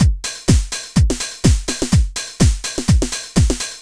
Index of /musicradar/retro-house-samples/Drum Loops
Beat 10 Full (125BPM).wav